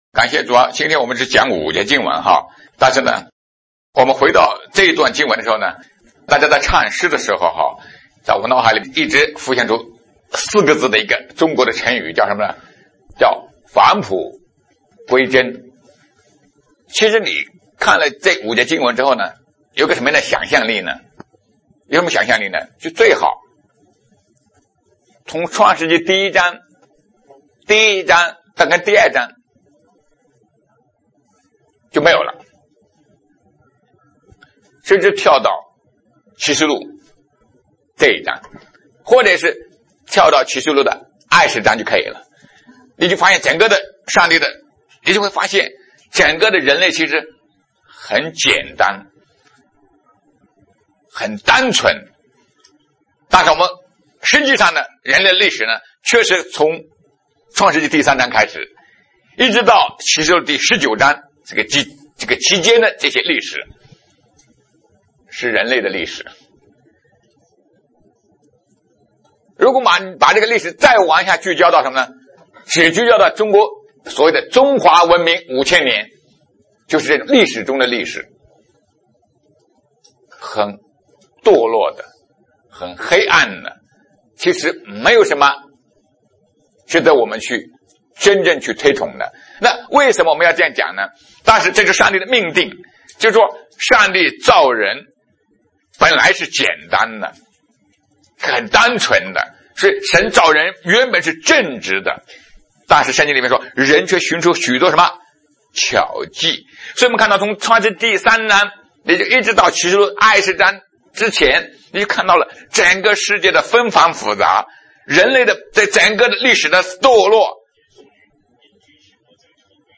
启示录第三十二讲—新乐园 2020年11月29日 下午5:51 作者：admin 分类： 启示录圣经讲道 阅读(6.32K